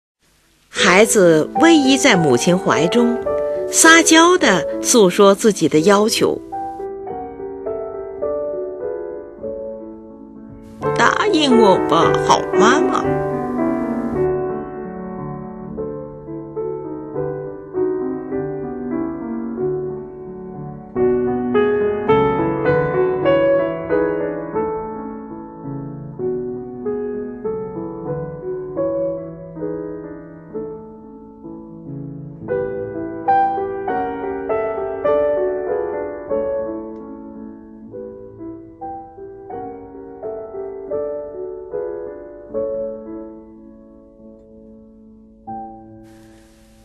是钢琴艺术史上的一部极为独特的作品
这是一段亲切温柔的抒情曲。旋律充满着孩童的稚气，就像小孩依偎在母亲的怀中。
乐曲的末尾，音调故意挂在属七和弦的七音上不予解决，就像孩子睁大着眼睛请求大人：“答应我行吗？”